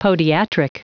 Prononciation du mot podiatric en anglais (fichier audio)
Prononciation du mot : podiatric